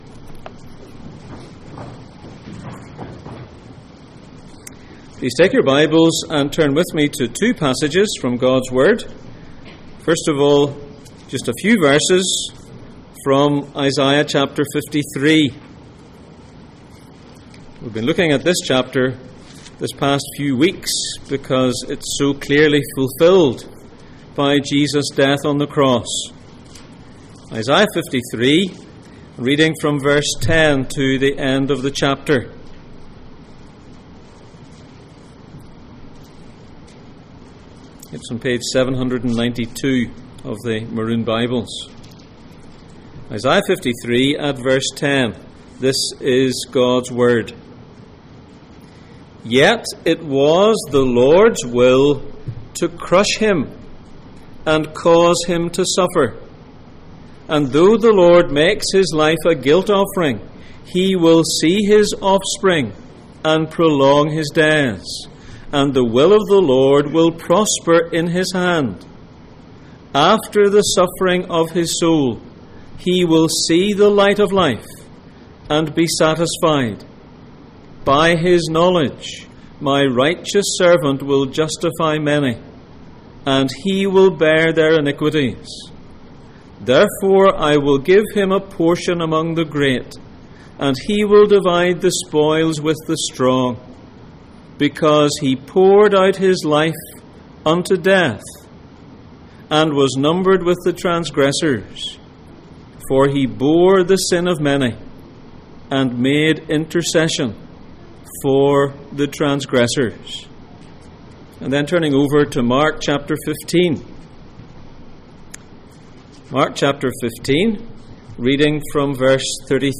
Jesus in Mark Passage: Mark 15:33-37, Isaiah 53:10-12 Service Type: Sunday Morning %todo_render% « Those around the cross